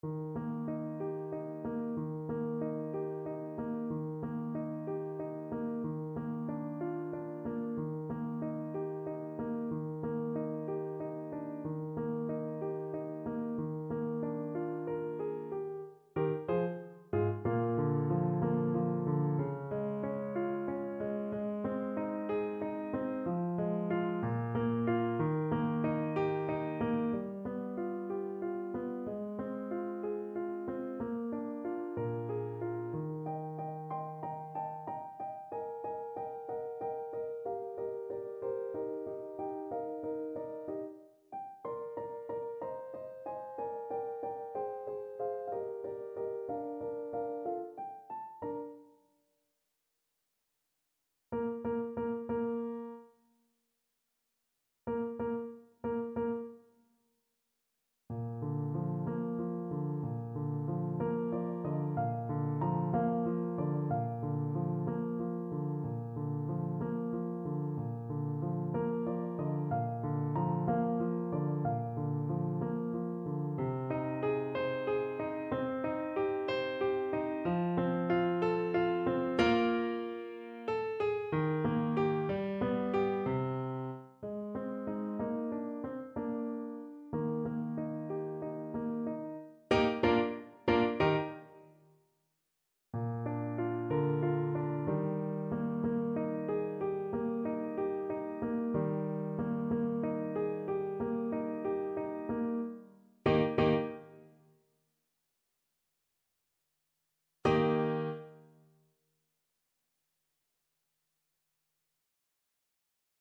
Symulacja akompaniamentu